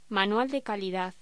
Locución: Manual de calidad
voz